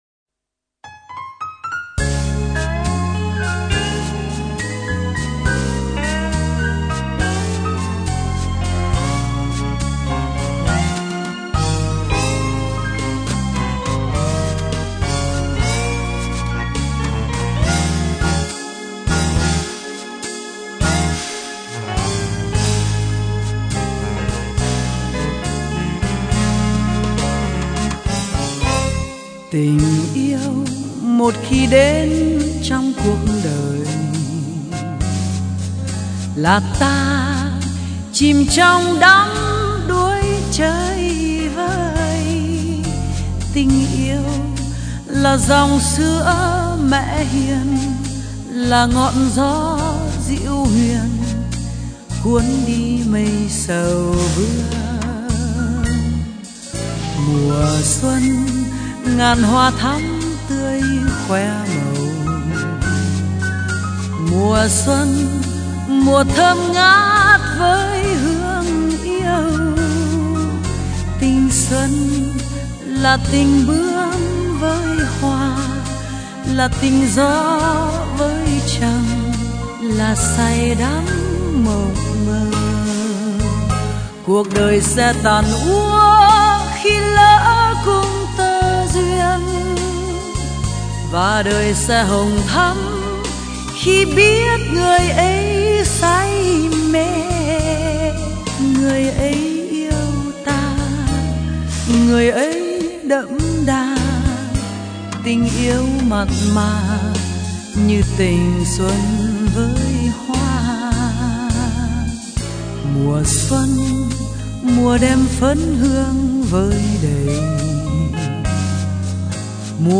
a little bluesy vibe